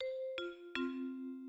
Train melody
The Swiss Federal Railways use three different jingles corresponding to the acronym of the company across three of the four national languages, transposed according to German note-naming conventions, with the final note as a chord. All three are played on the vibraphone:
CFF (French) written as "C - F - F"